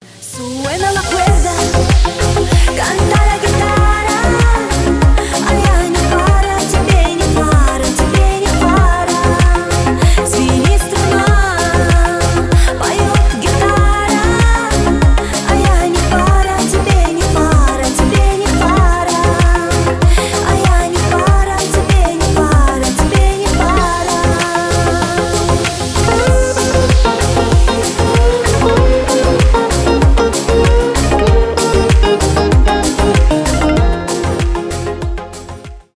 поп , танцевальные
кавказские